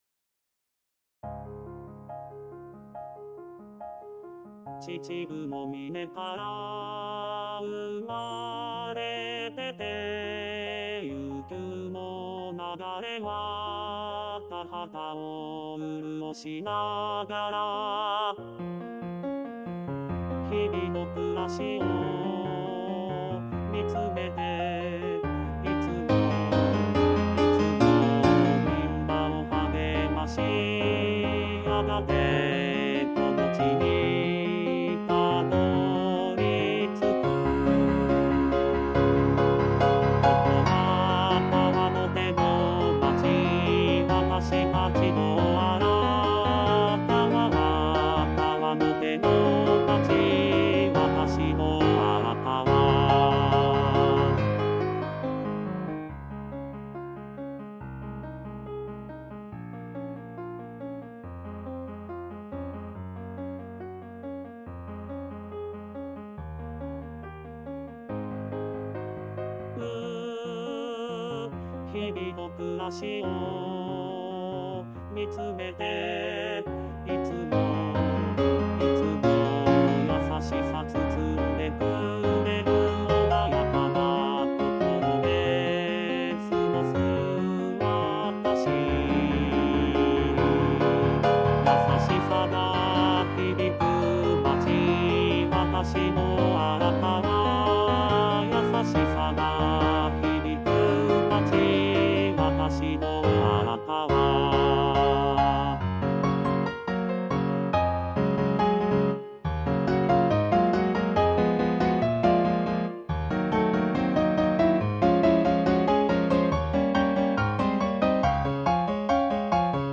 ●パート単独音源　　　■Rchソプラノ、Lch、アルト、テノール、バス
hibinokurasi_bassueonly.mp3